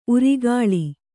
♪ urigāḷi